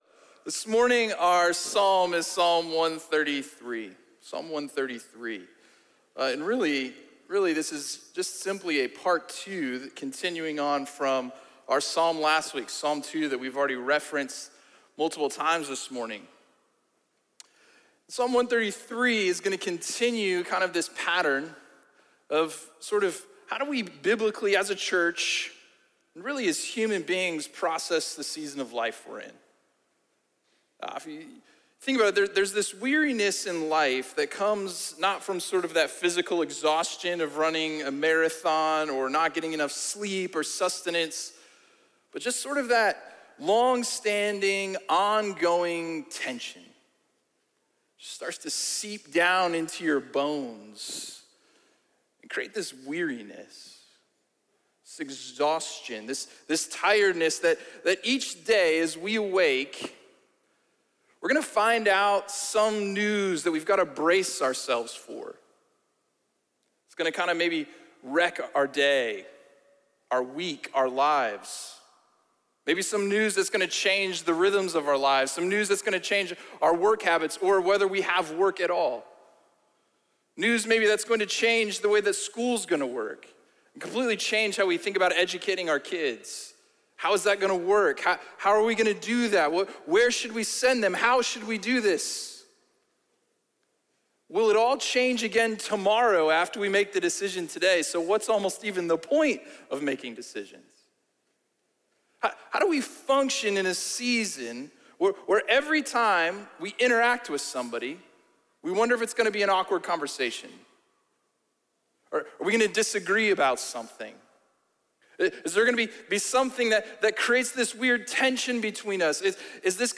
A message from the series "Behold Him."